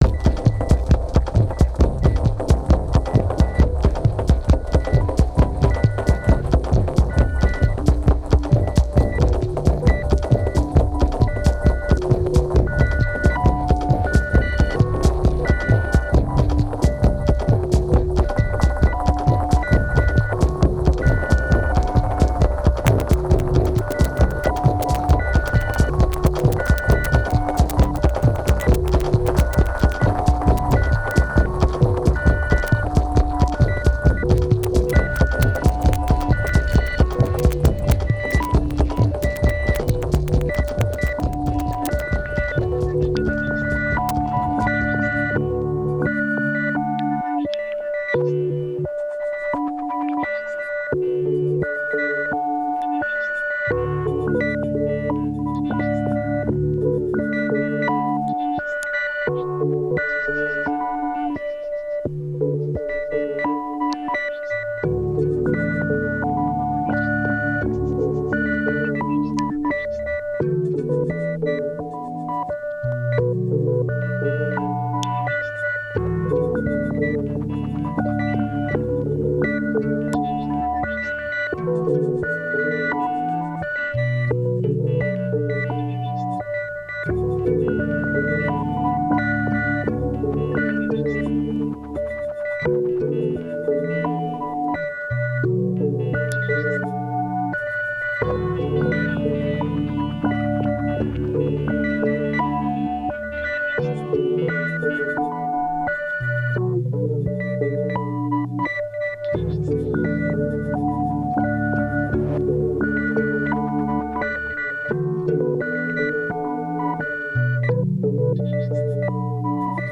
Electronic Experimental